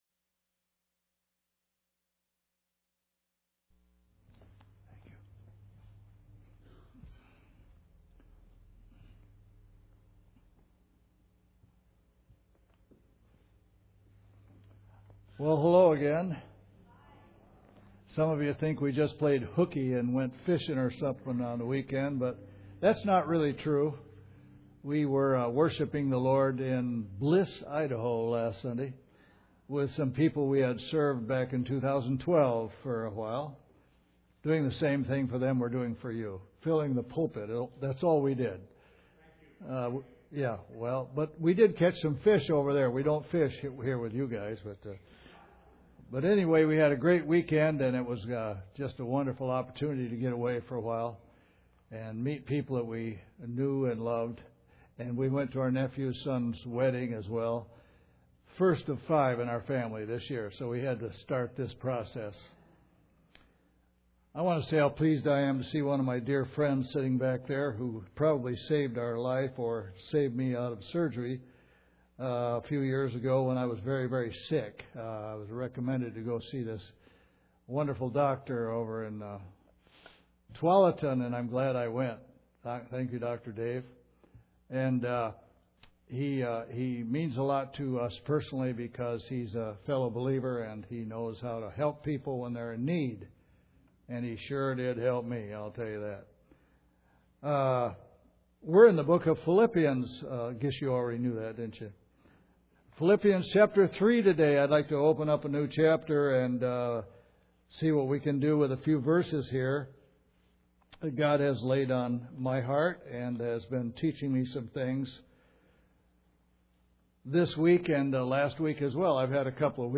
Passage: Philippians 3:1-3 Service Type: Sunday Service